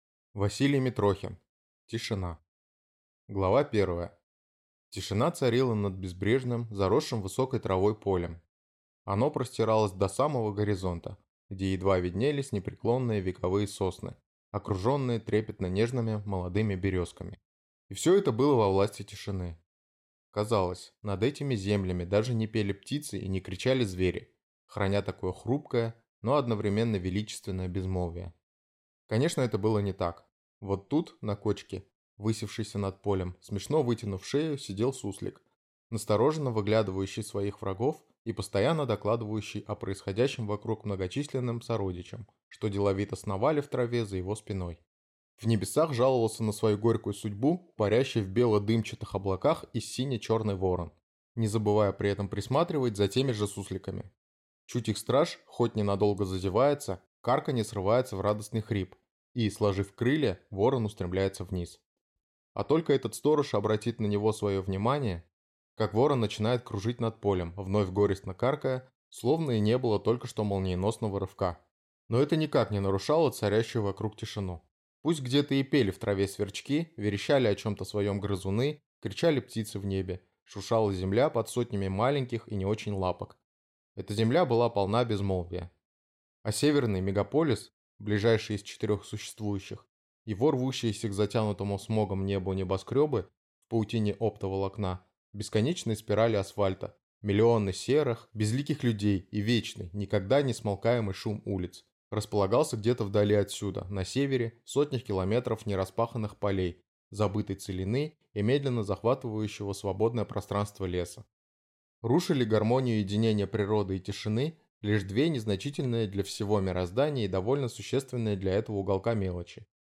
Аудиокнига Тишина | Библиотека аудиокниг